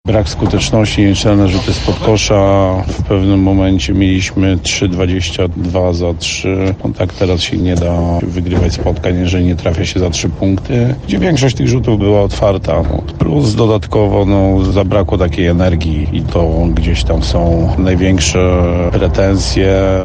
mówił po meczu